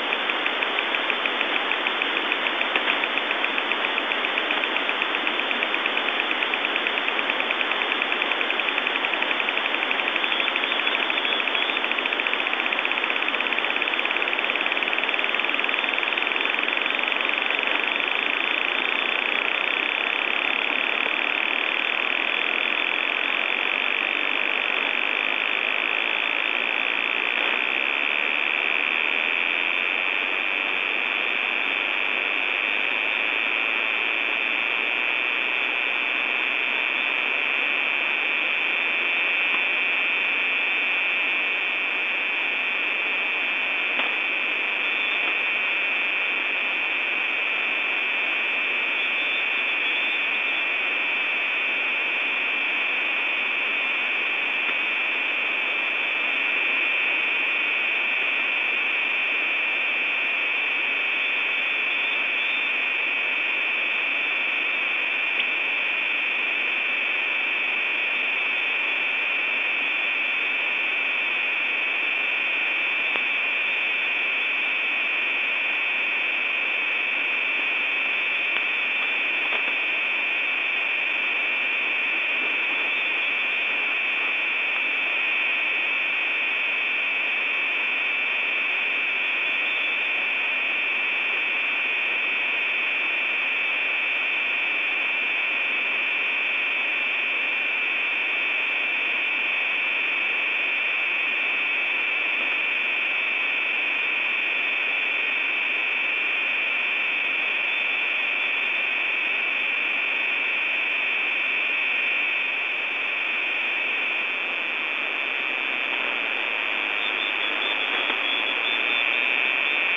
Странный сигнал на "двадцатке"
Начало » Записи » Радиоcигналы на опознание и анализ